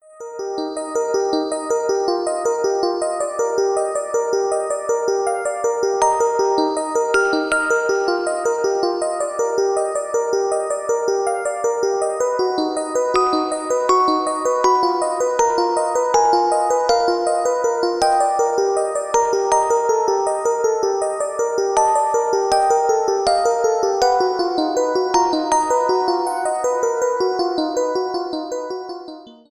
faded out the last two seconds